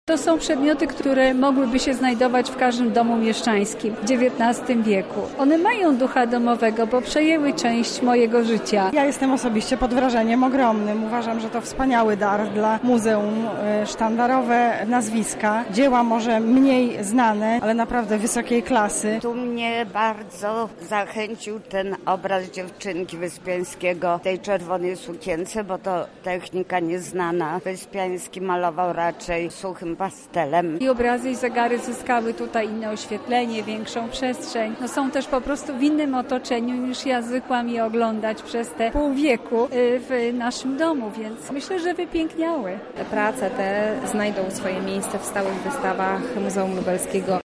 Wrażenia towarzyszące uczestnikom wernisażu możemy usłyszeć w krótkiej relacji naszej reporterki